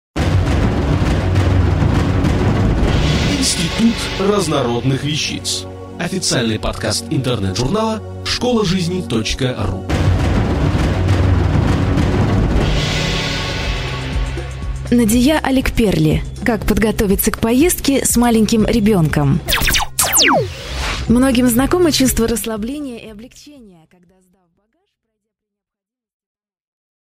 Аудиокнига Как подготовиться к поездке с маленьким ребенком?